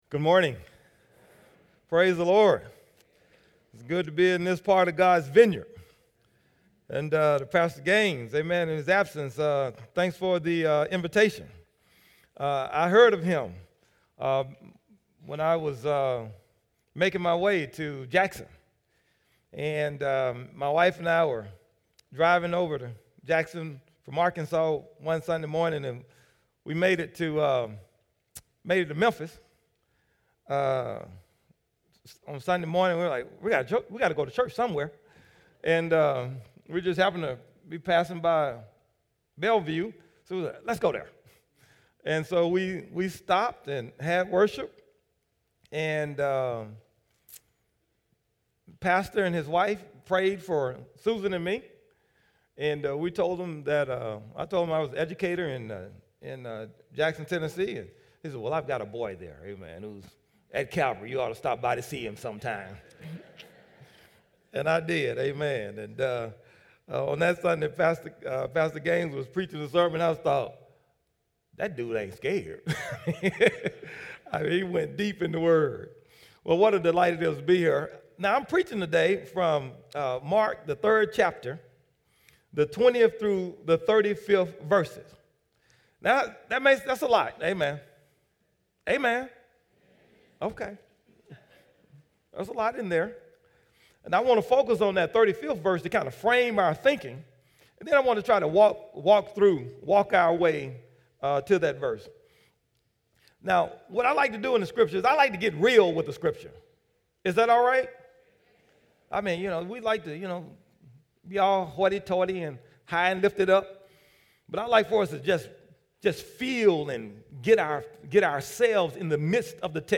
sermon7-15-18.mp3